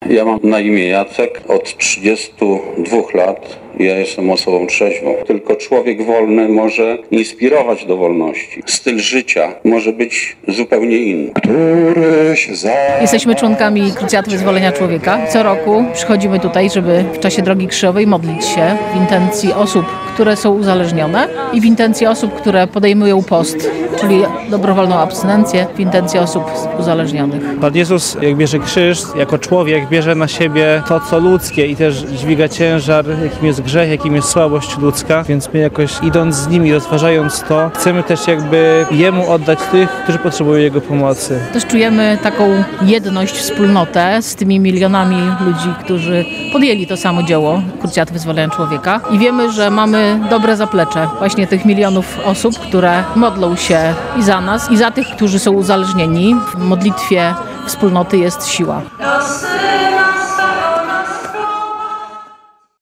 Ulicami Starego Miasta w Lublinie przeszła droga krzyżowa w intencji osób uzależnionych.